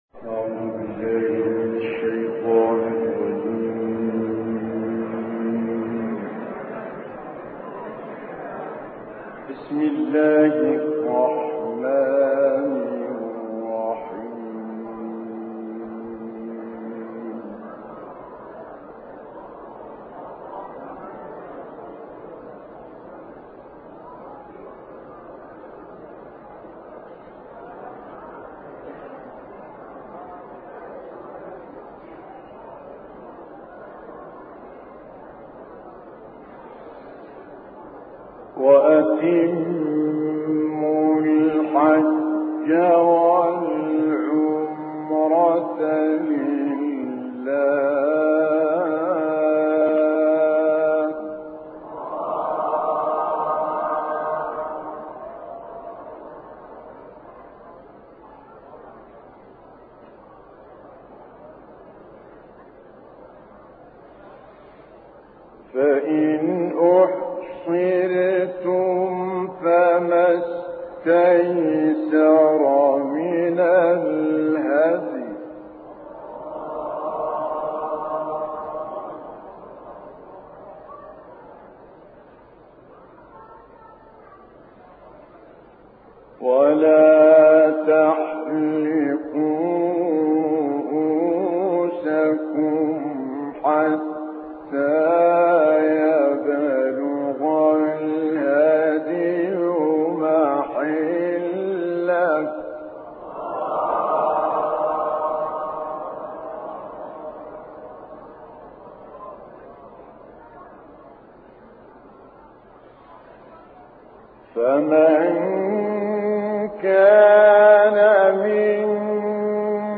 تلاوت آیات 196 تا 203 سوره بقره با صدای مرحوم متولی عبدالعال.